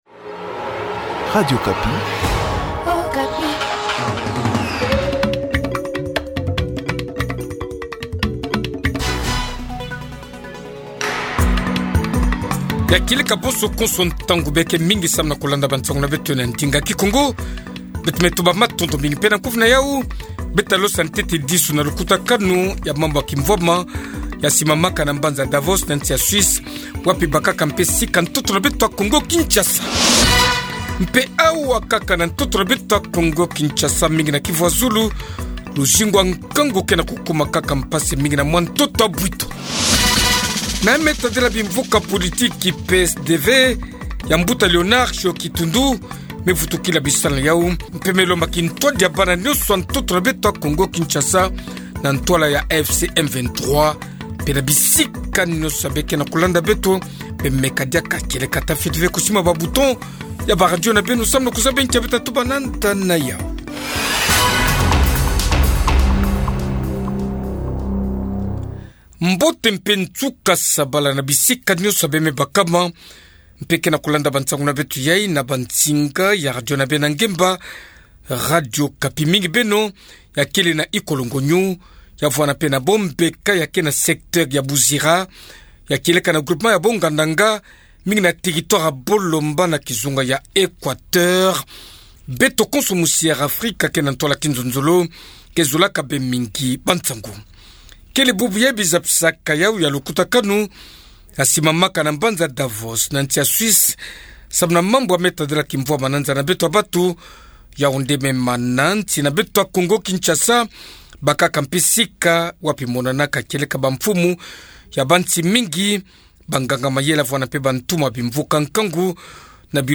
Journal soir Kikongo